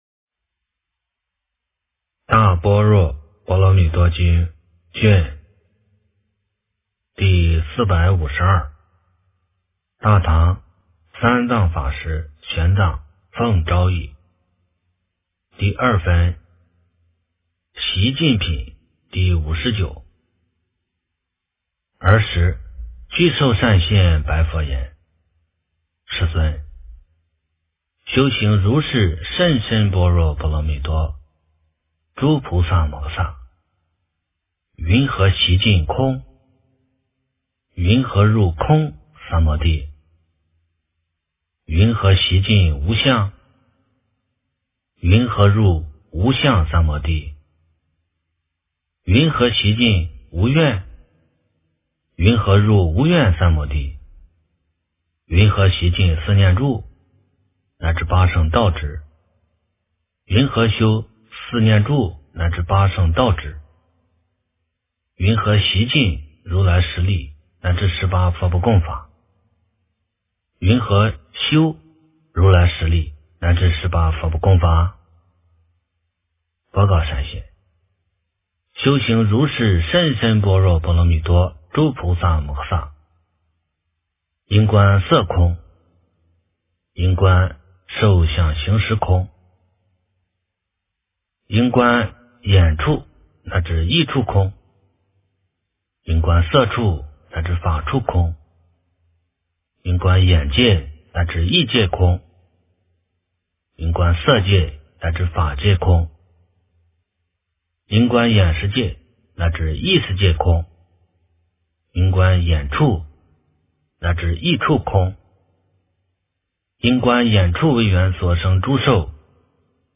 大般若波罗蜜多经第452卷 - 诵经 - 云佛论坛